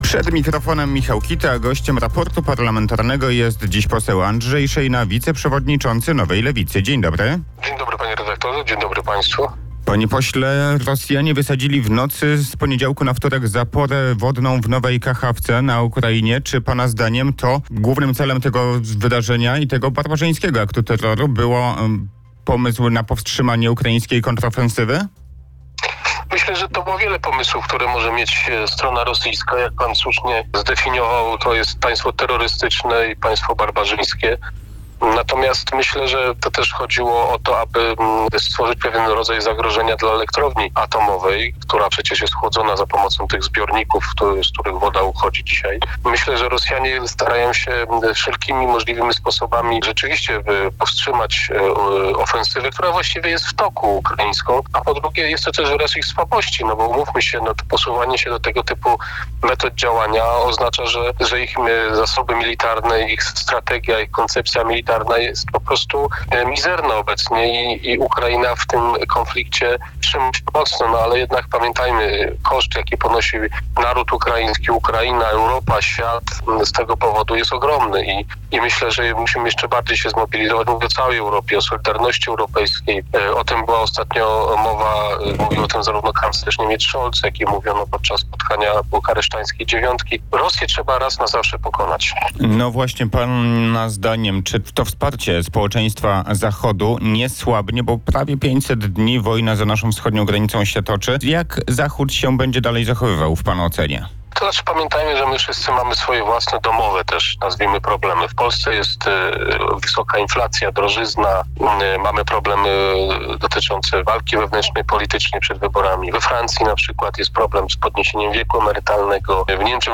– Rosja to państwo terrorystyczne i barbarzyńskie. Myślę że jednym z celów wysadzenia zapory wodnej na Dnieprze mogło być powstrzymanie ukraińskiej kontrofensywy – stwierdził w Raporcie Parlamentarnym na antenie Radia Kielce poseł Andrzej Szejna, świętokrzyski parlamentarzysta Nowej Lewicy.